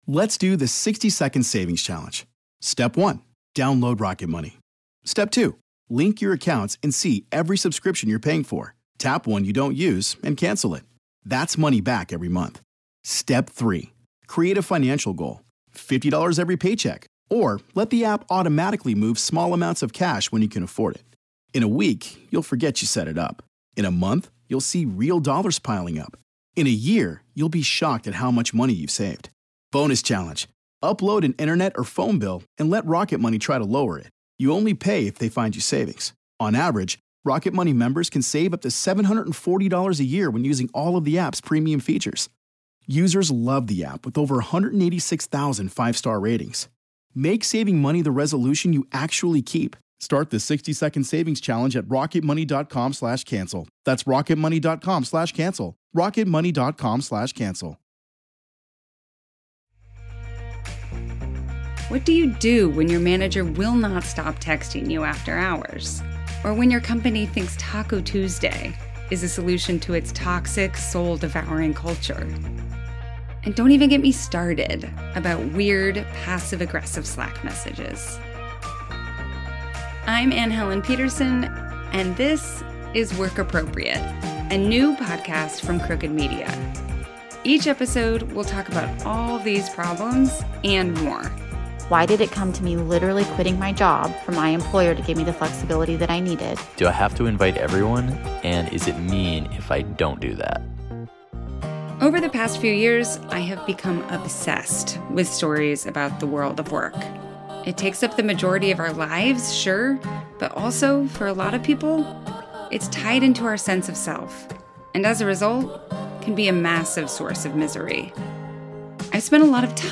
Inspired by her immensely popular newsletter, author Anne Helen Petersen turns her attention to the wild world of work in Work Appropriate. Featuring guest appearances by the smartest people Anne knows, the show delivers humorous but practical workplace advice for a range of listener questions.